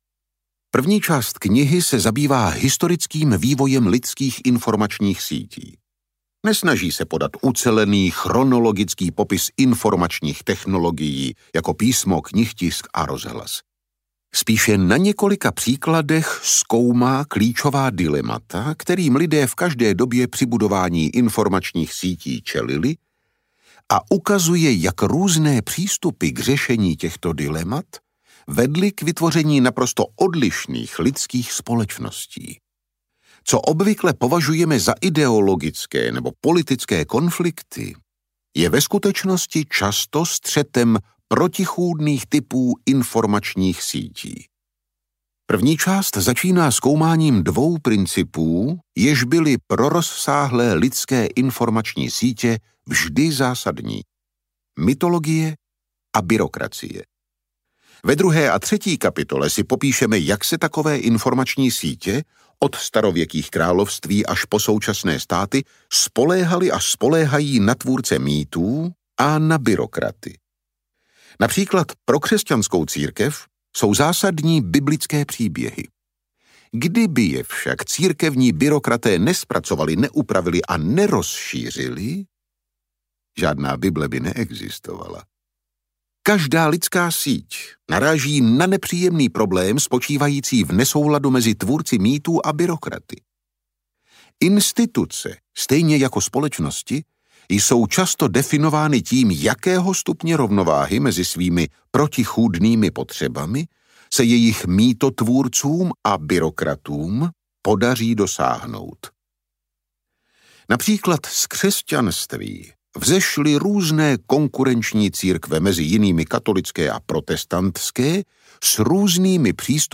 Audiokniha Nexus - Yuval Noah Harari | ProgresGuru